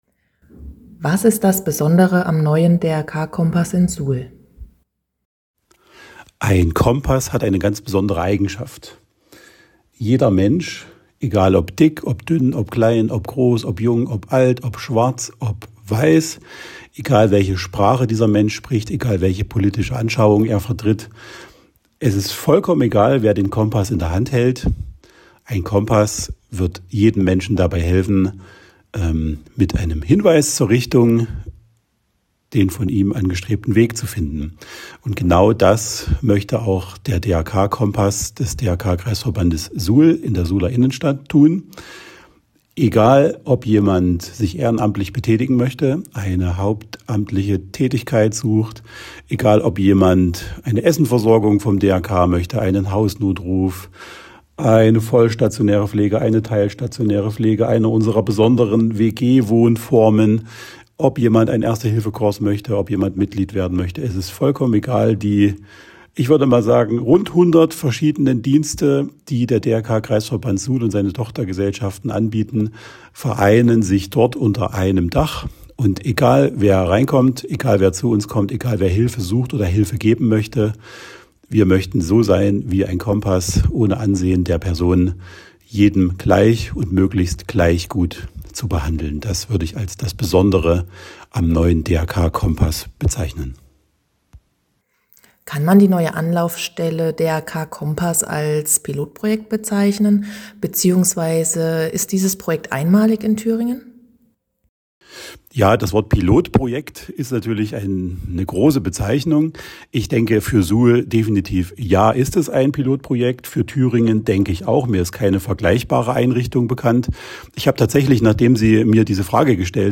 Interview_DRK_Kompass.mp3